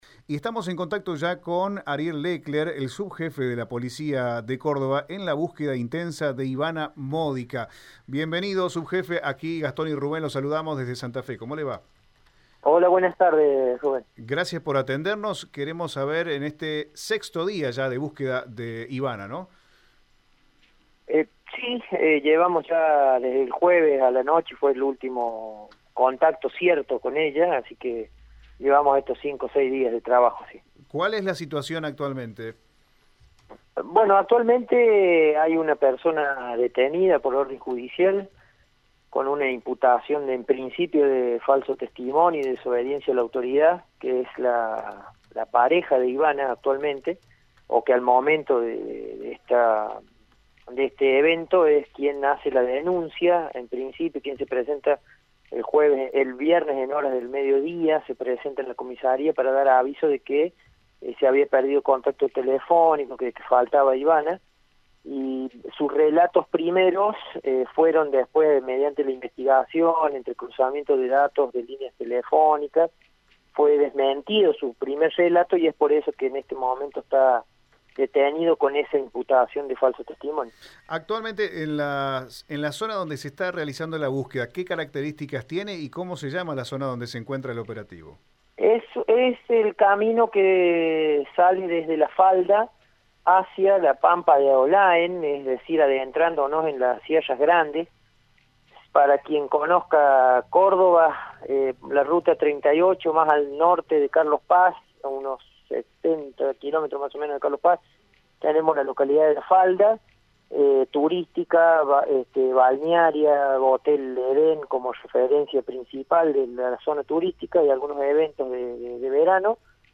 Escuchá la entrevista con el subjefe de la Policía de Córdoba, comisario general Ariel Lecler en Radio EME:
El-subjefe-de-la-Policía-de-Córdoba-comisario-general-Ariel-Lecler.mp3